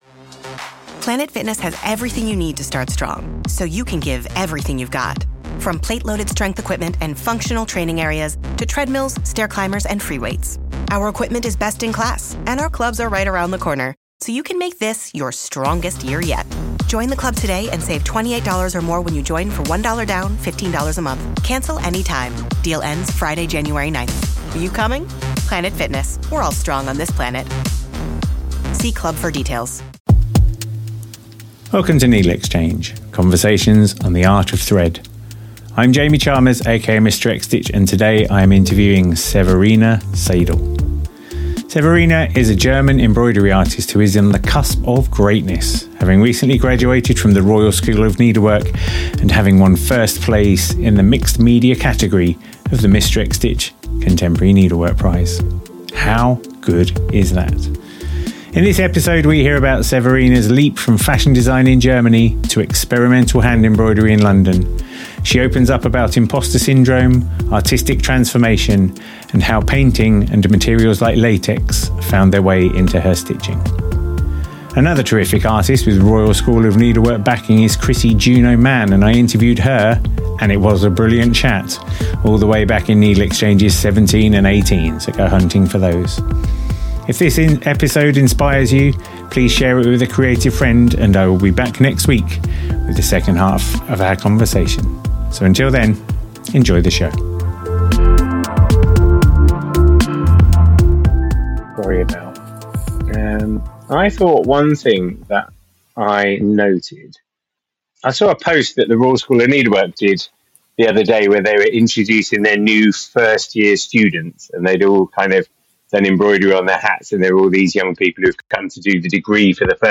About NeedleXChange: NeedleXChange is a conversation podcast with embroidery and textile artists, exploring their process and practice.